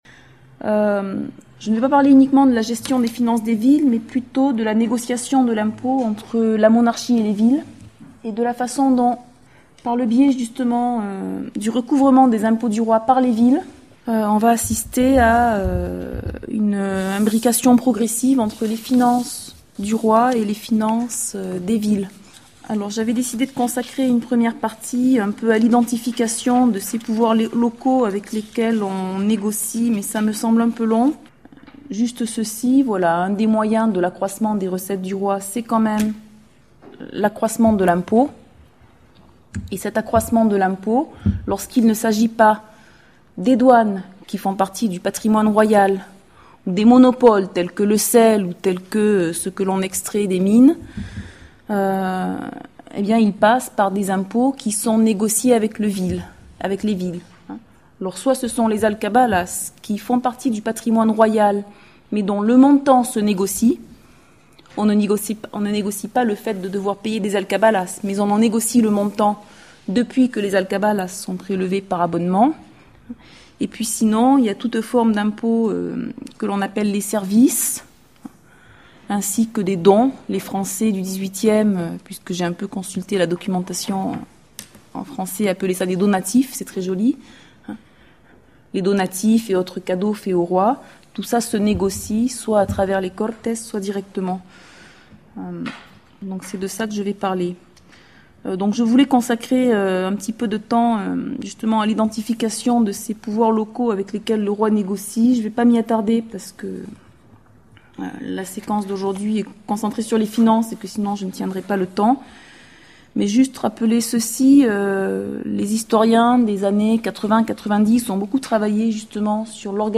L'essentiel de l'exposé est consacré à la négociation des contributions versées par les royaumes au roi et s'intéresse avant tout aux stratégies développées par les différentes acteurs de cette négociation et à leurs interprétations de cette négociation. En Castille, celle-ci se joue entre trois acteurs, la couronne, les Cortes et les villes qui ont le privilège d'y envoyer des délégués.